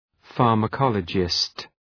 Προφορά
{,fɑ:rmə’kɒlədʒıst}